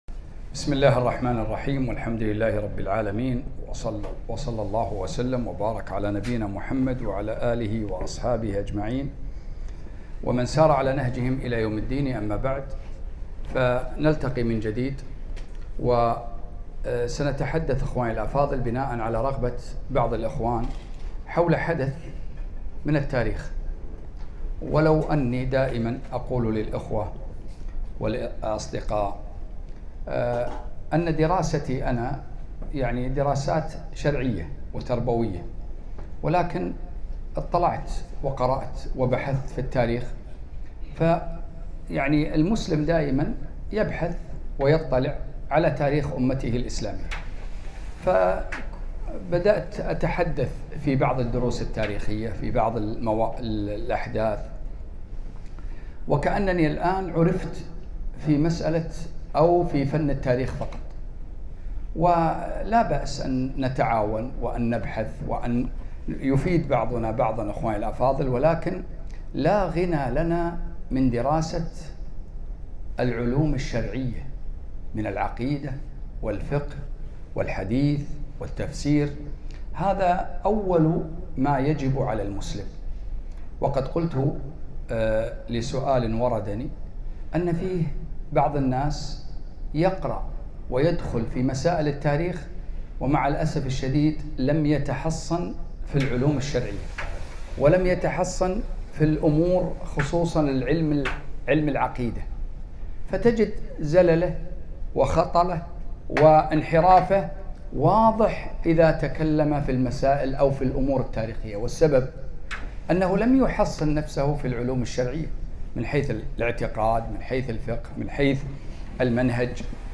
محاضرة - [ الـعــبــرة مـن مُــلــــوك الــطــوائـــف ]